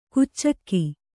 ♪ kuccakki